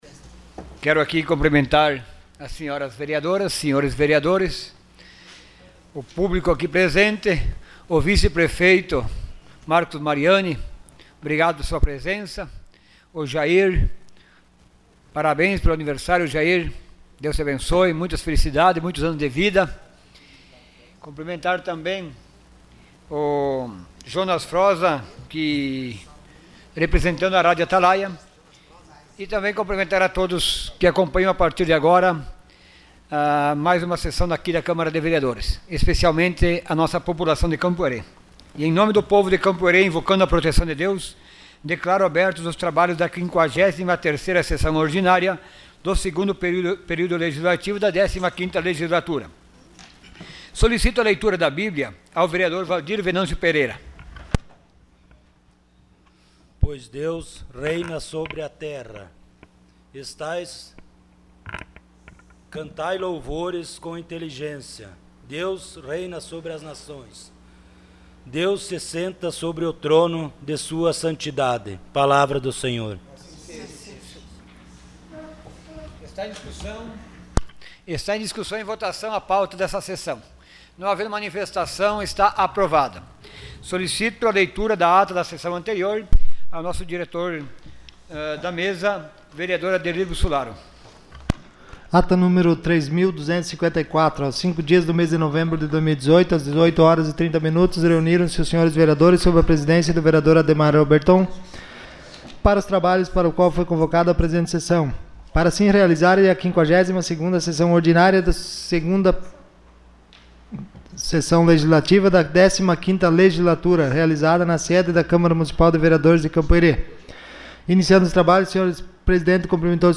Sessão Ordinária dia 07 de novembro de 2018.